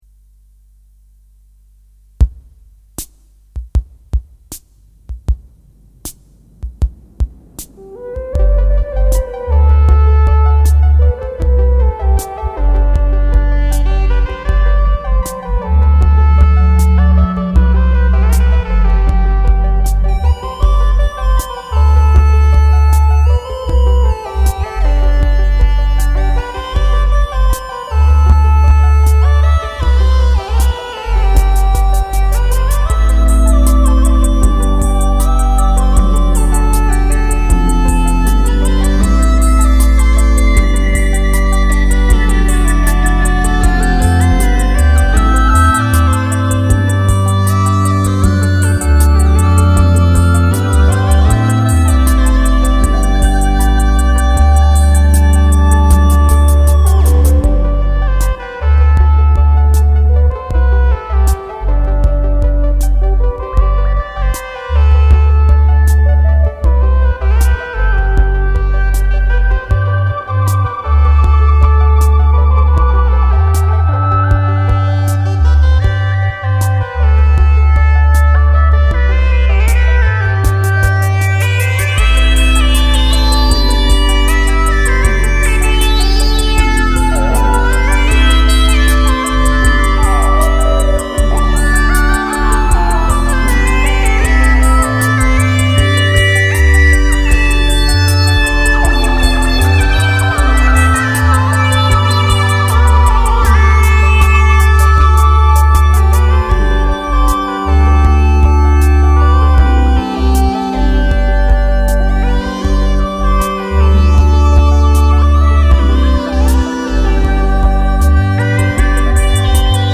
Voilà une compo qui n'est pas faite avec le MS-20, encore moins avec le Virtual MS-20. C'est plutôt le cousin du MS-50.
Bref, un petit morceau pas house, ni hardtech, pas non plus trance.
Le tout a été réalisé avec un MS-10 comme source sonore, aucun séquenceur (je n'en possède même pas...), donc tout joué "à la main" (veuillez m'excuser des imprécisions).
Le rythme vient d'une petite Roland CR-68.
Il est bien bruyant, ça s'entend lors de l'enregistrement, mais j'aime bien le son et la chaleur qu'il ajoute, y compris l'overdrive forcé quand le gain est un peu élevé...